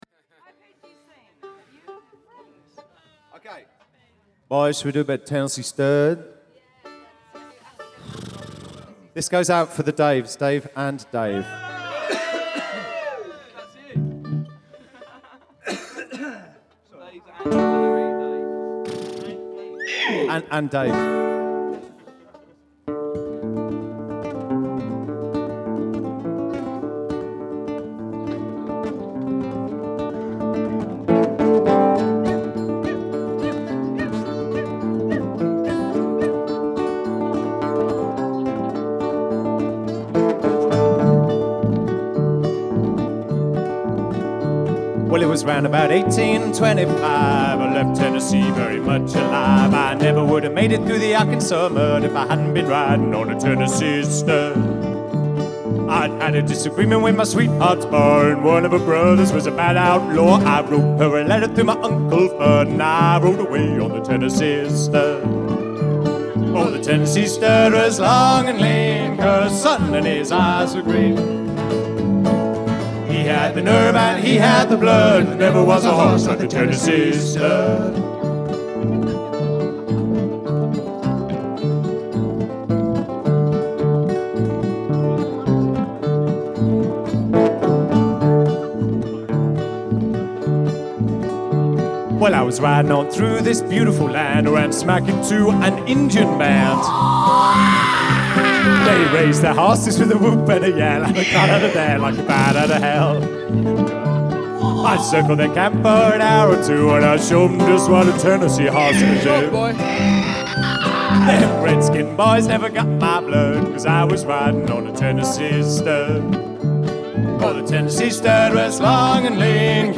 Recorded live at Rollright Fayre